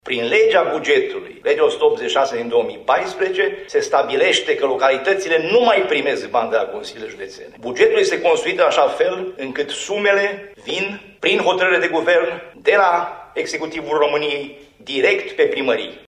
Subiectul a fost abordat astăzi, în cadrul unei conferinţe de presă susţinută la Reşiţa, de către Preşedintele Consiliului Judeţean Caraş-Severin, Sorin Frunzăverde.